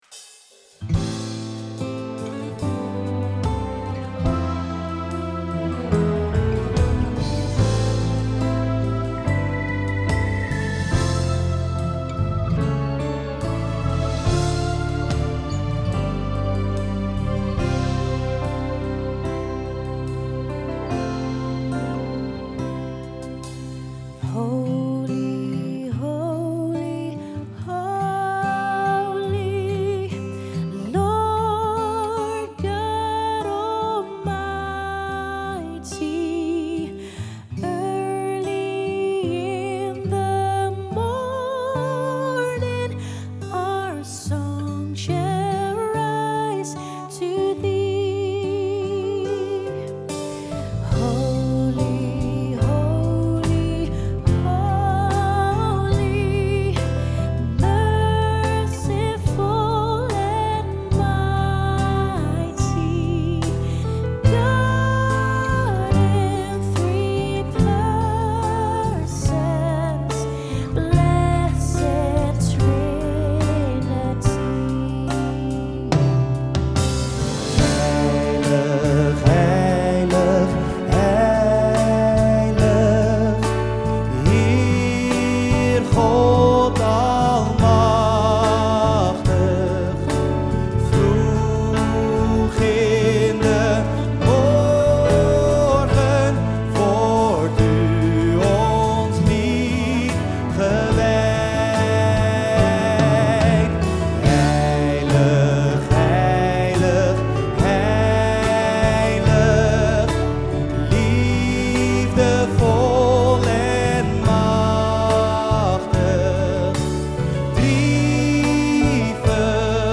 Genre Live Praise & Worship